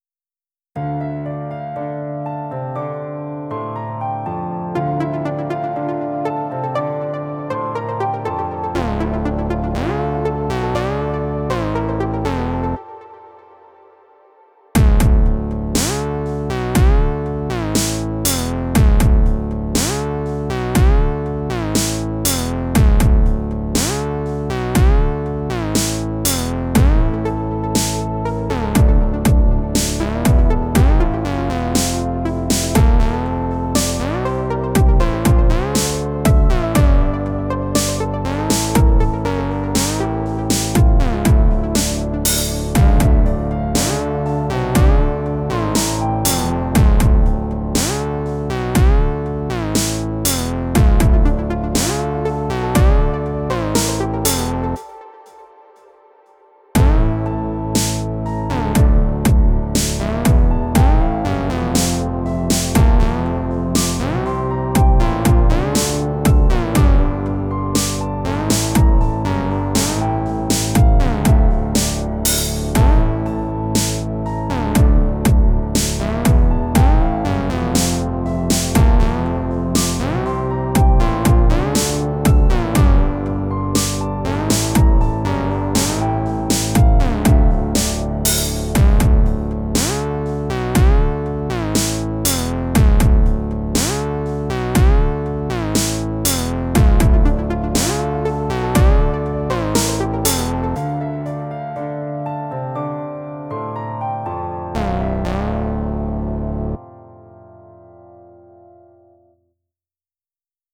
ELECTRO G-N (38)